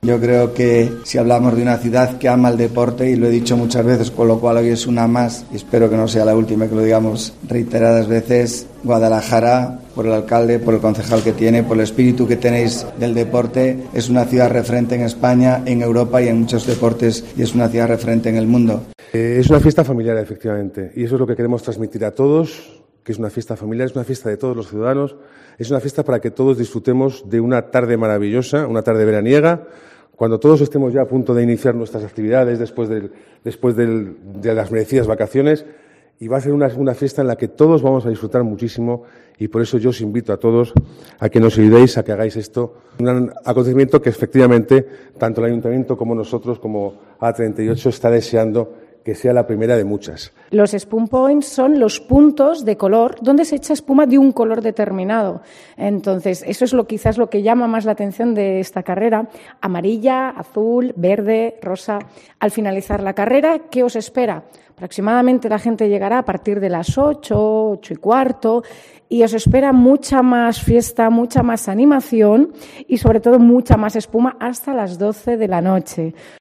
Informativos Guadalajara